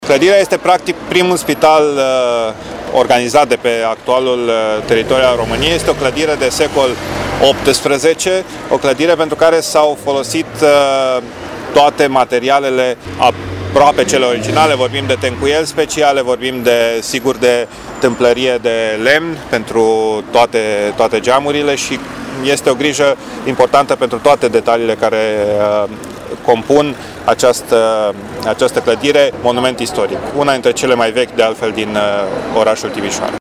Investiţia în valoare de un milion de lei a fost realizată cu respectarea strictă a regulilor din domeniul monumentelor istorice, spune viceprimarului Dan Diaconu.